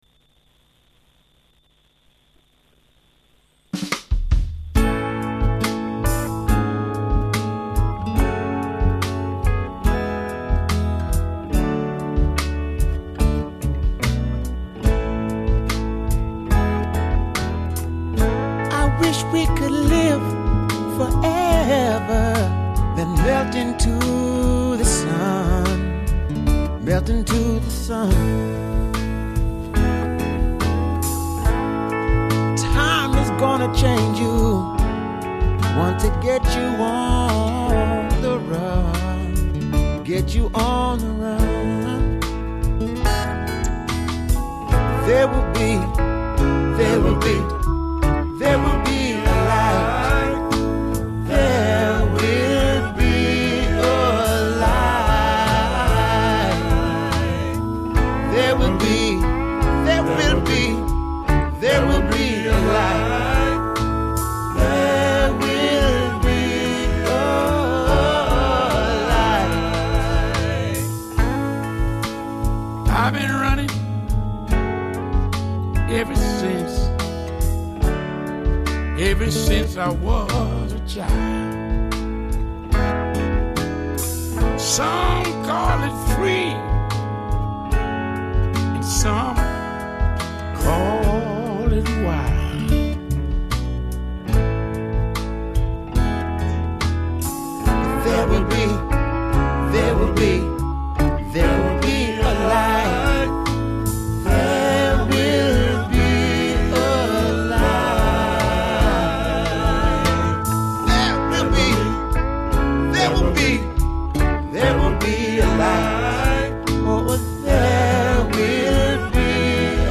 FaithMatters Music to uplift,Inspire or just bring a point of difference to your day